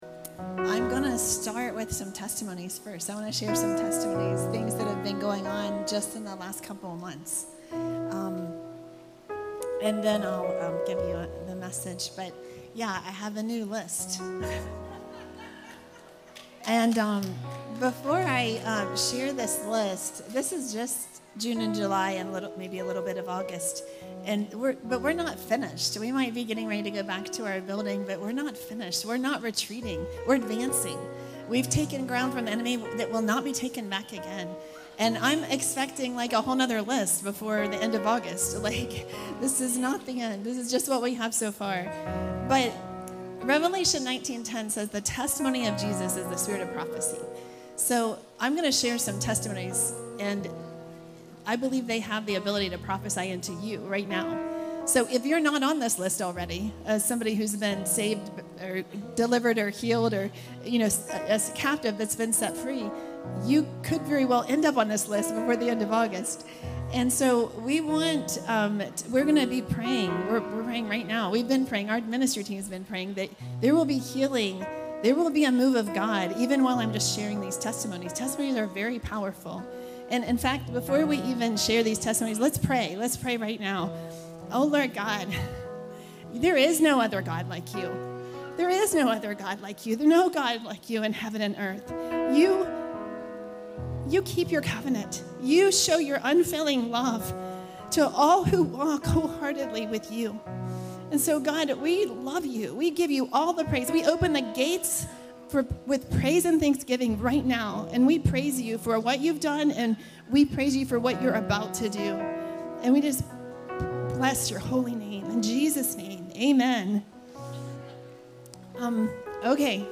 Cleansing The Temple - Stand Alone Messages ~ Free People Church: AUDIO Sermons Podcast